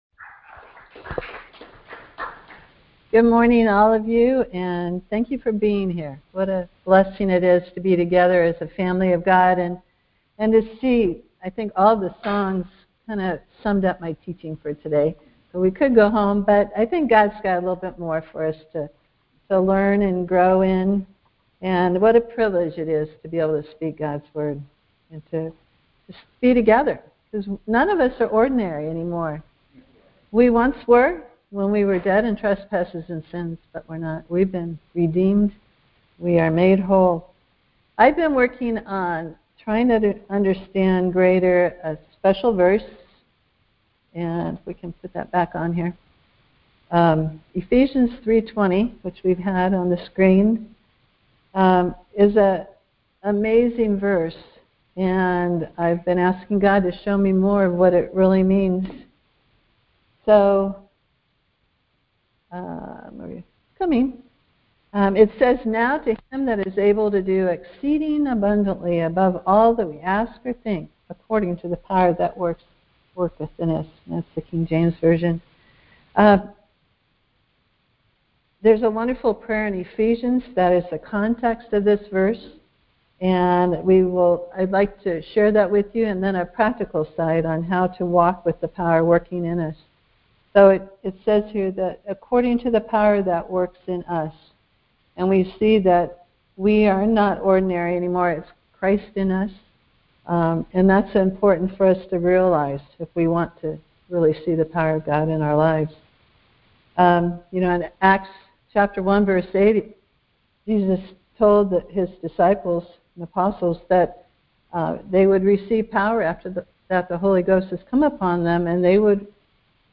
Live in the Moment Details Series: Conference Call Fellowship Date: Tuesday, 02 March 2021 Hits: 551 Play the sermon Download Audio ( 4.96 MB ) Are You Expecting Enough?